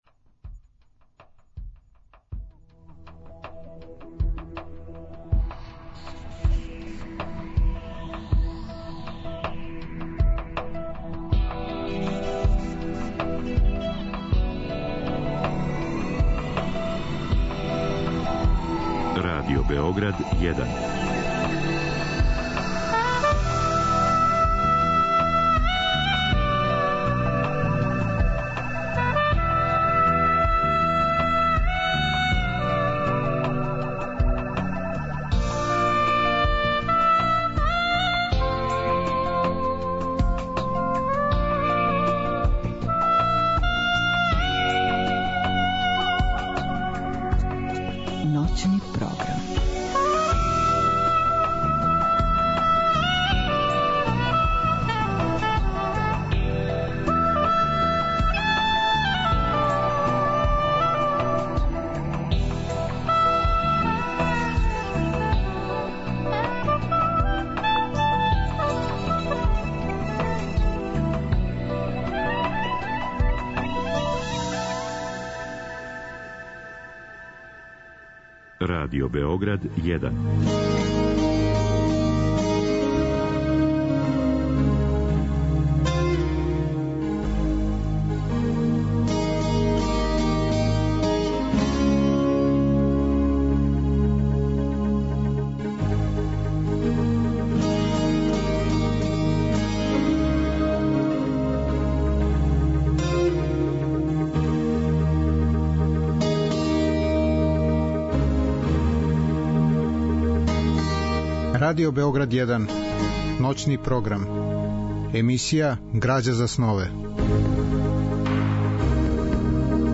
Слушаћемо тонске записе разговора са писцима који су начињени у Тршићу за време трајања колоније.